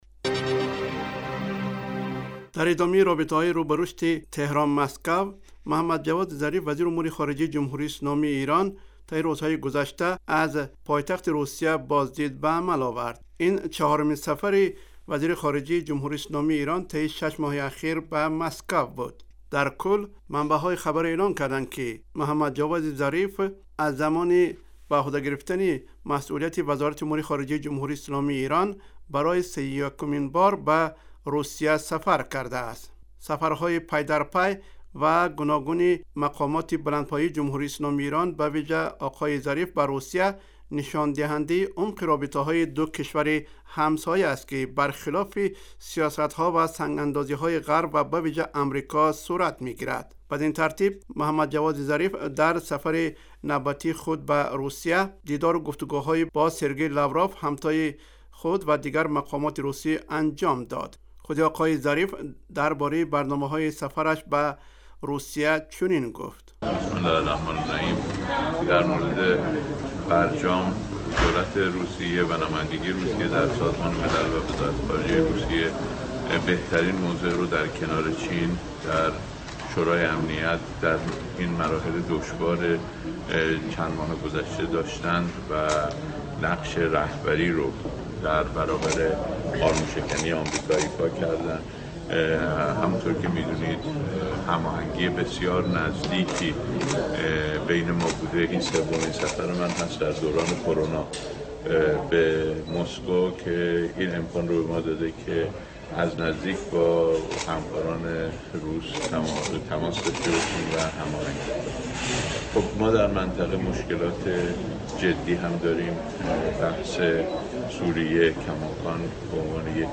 گزارش ویژه: گسترش روز افزون روابط ایران و روسیه